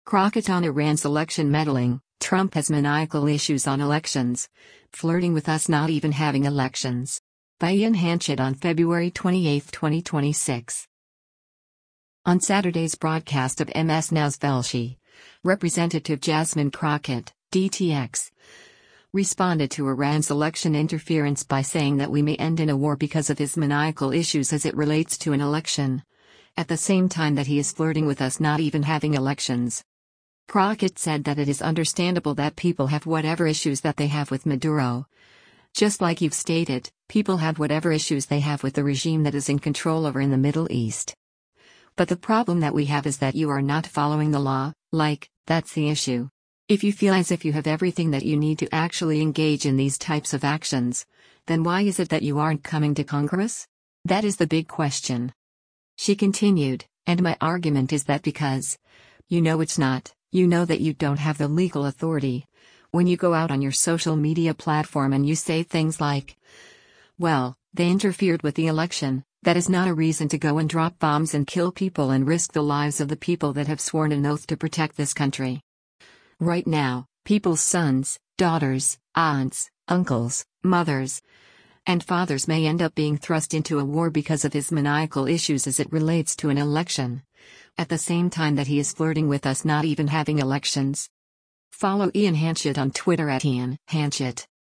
On Saturday’s broadcast of MS NOW’s “Velshi,” Rep. Jasmine Crockett (D-TX) responded to Iran’s election interference by saying that we may end in a “war because of his maniacal issues as it relates to an election, at the same time that he is flirting with us not even having elections.”